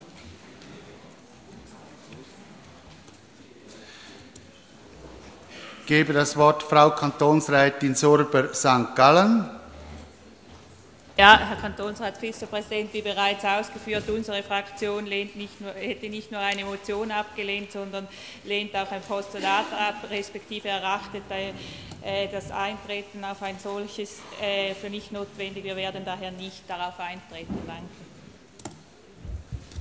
16.9.2013Wortmeldung
Session des Kantonsrates vom 16. bis 18. September 2013